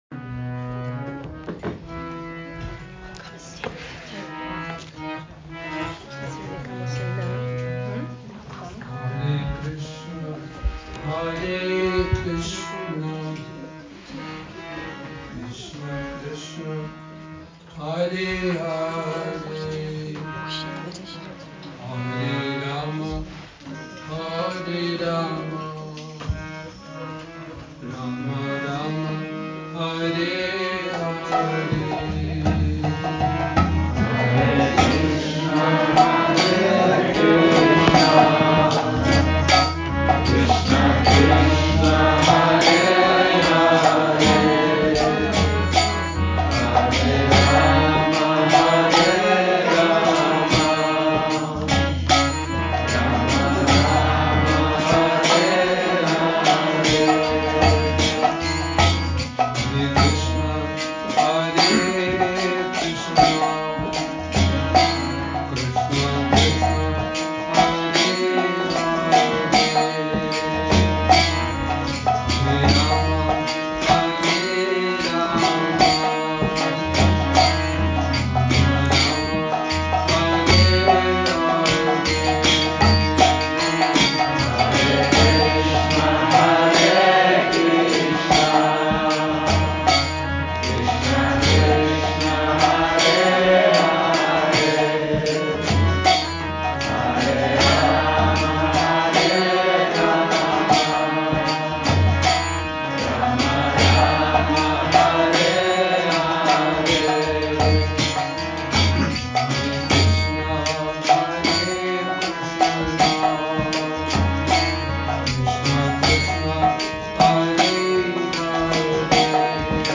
Kírtan – Krišnův Dvůr